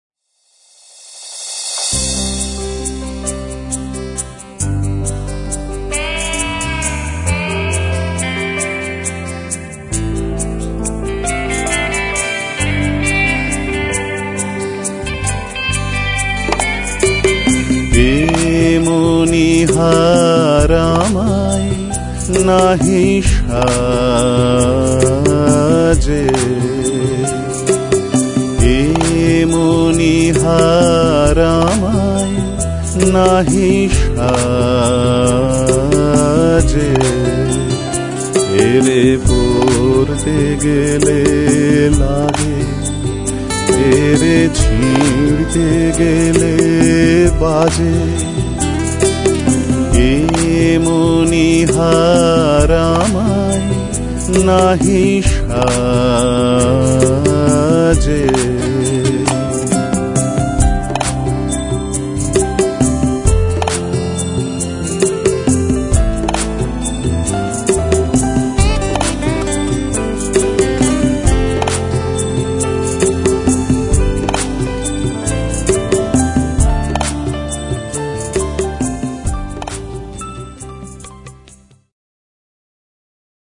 Bass, Acoustic and Electric Guitar
Tabla and Percussion